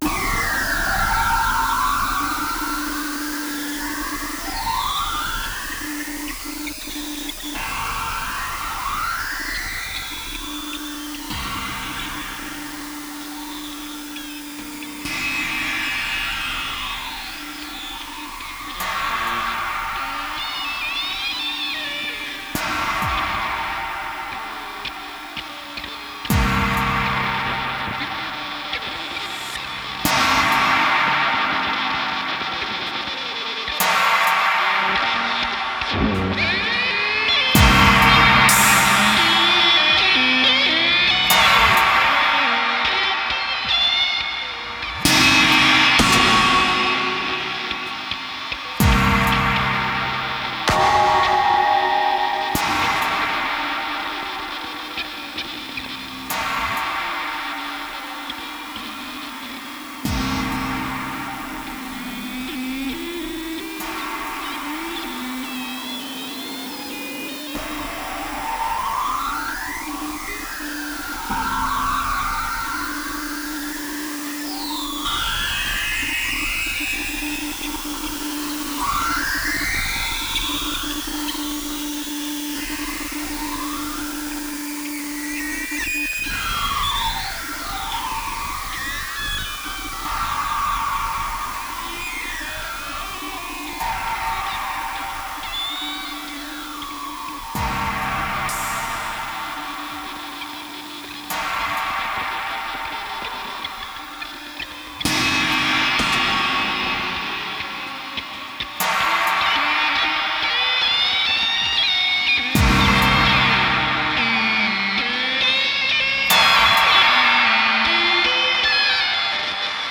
生物的な荒々しく生々しいファズ・サウンドと、近未来的なアーバン・ビートの綴れ織り。
生死無境の美を感じさせる響きに満ちています。
たいへんデリケートで複雑なパンニングと、スペクトル分布を特徴とするアルバムです。